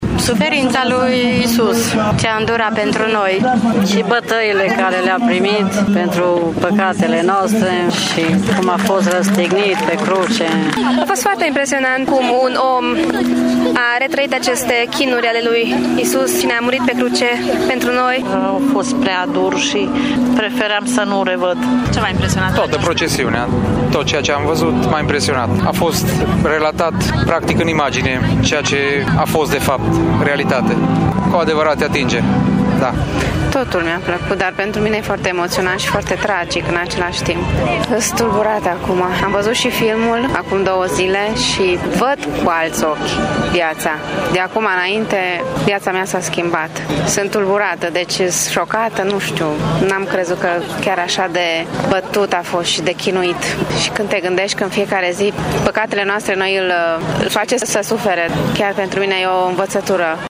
Târgumureșenii au fost foarte impresionați de scenele la care au asistat iar unii spun că această experiență le va schimba viața: